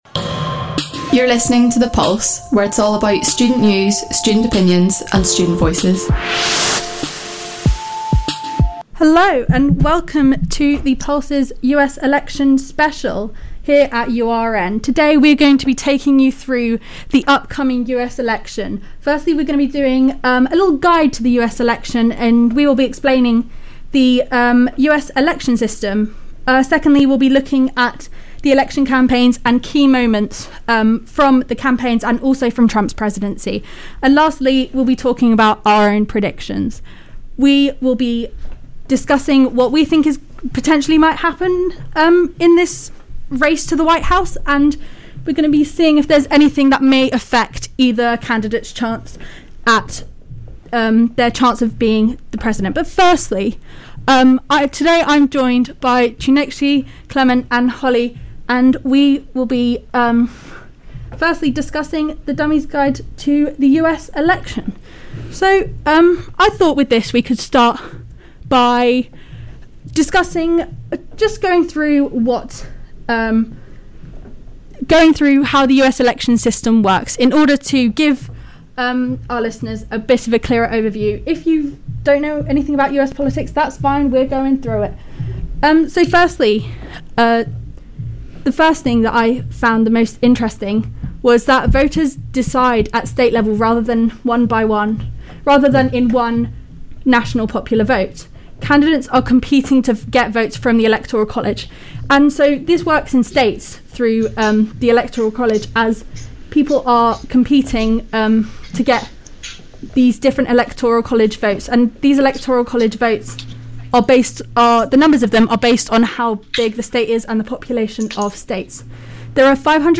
The panel give listeners a simple guide on how the electoral system works, discuss key moments from both the election campaigns and Trump's presidency and try to predict who will win the race to the White House!